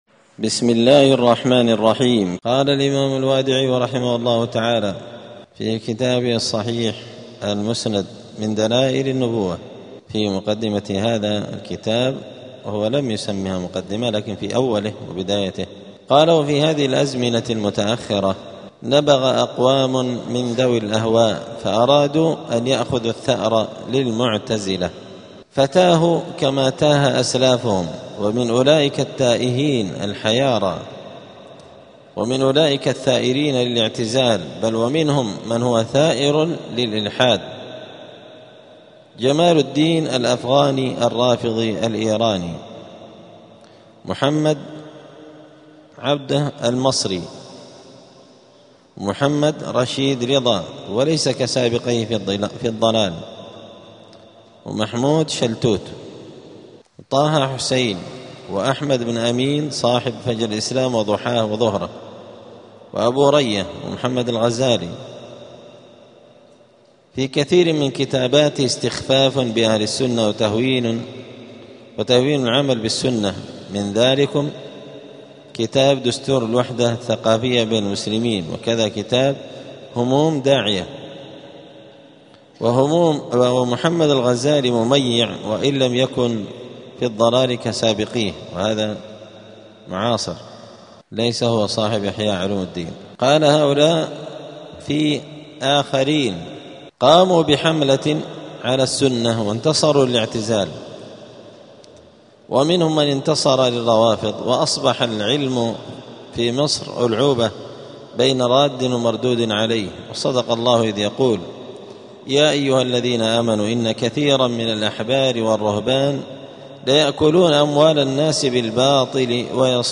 *الدرس الثاني (2) {تتمة مقدمة المؤلف}.*